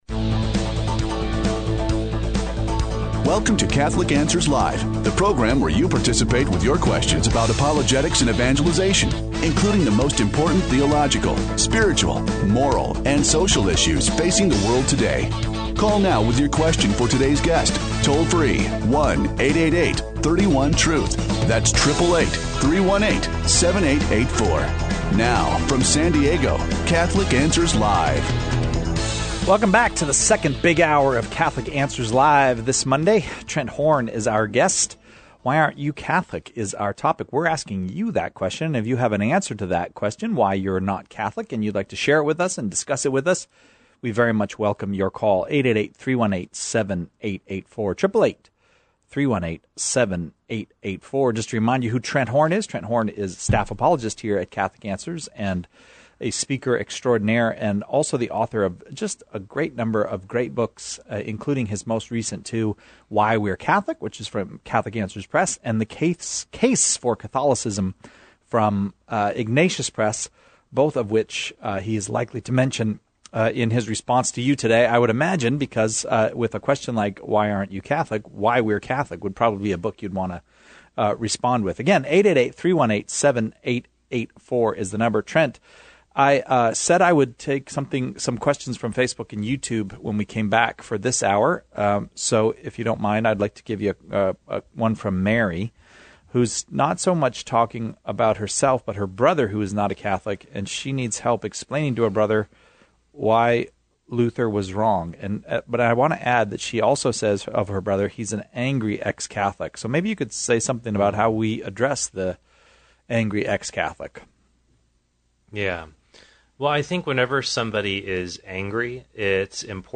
Their answers spark some unexpected conversations.